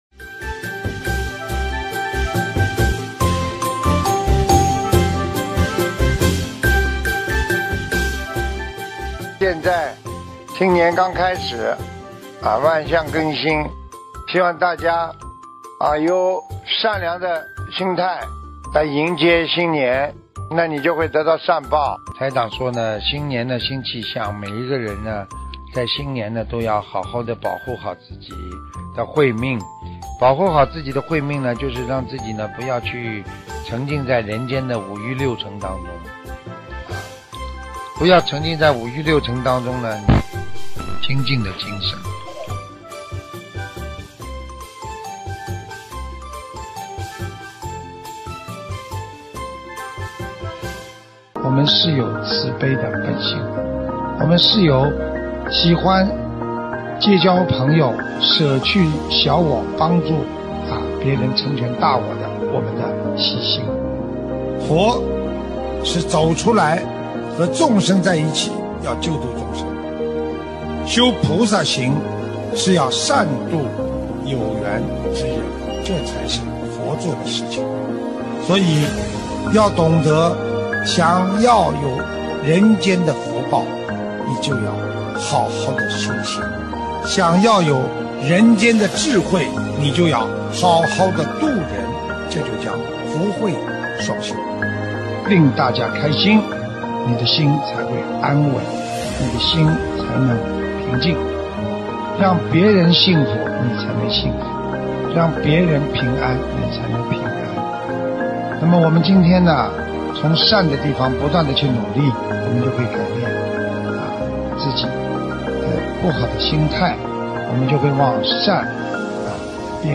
音频：德国观音堂春节上头香花絮！2023年！